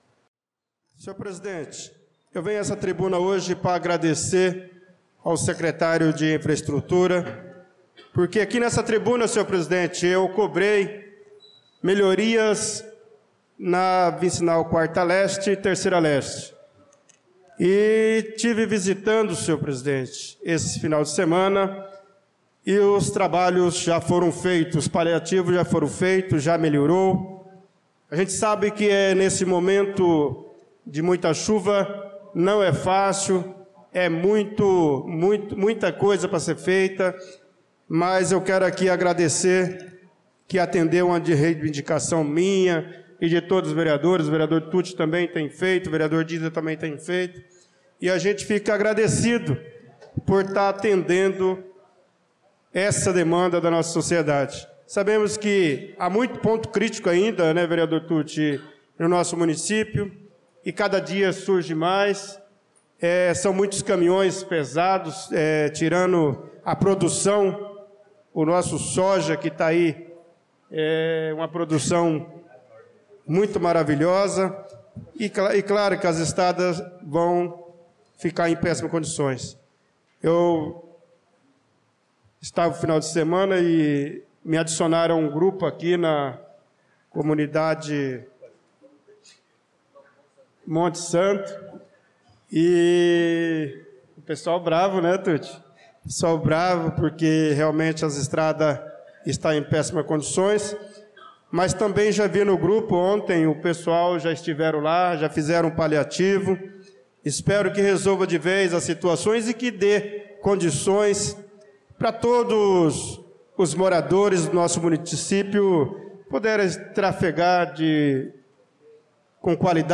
Pronunciamento do vereador Bernardo Patrício na Sessão Ordinária do dia 18/02/2025